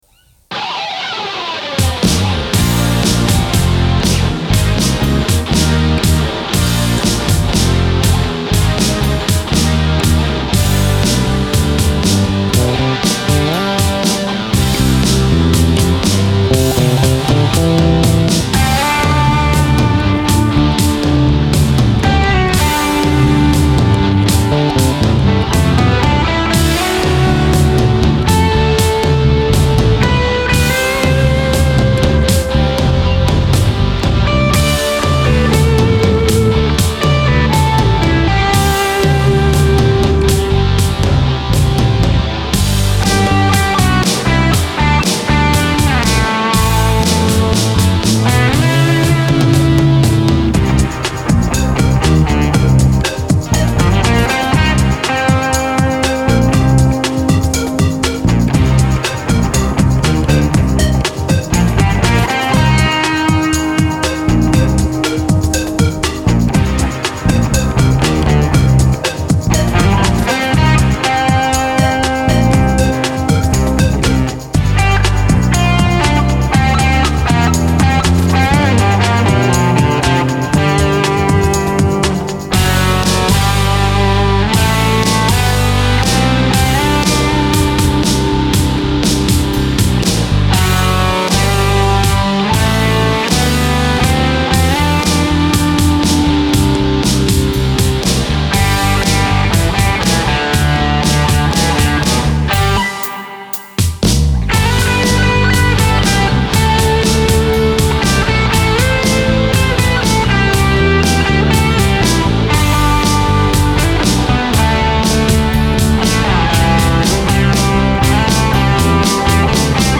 Einmal mit dem Reson Rack, bestückt mit dem YOKO Bandsplitter und dreimal den Mastering Bus Compressor; und einmal mit dem Cubase Multiband Compressor und dem Reason Rack Plugin nur mit dem Maximizer.
Reason Mastering YOKO und 3 mal Master Bus Compressor:
Hanging_Rock_9_YOKO_Mastering_mod.mp3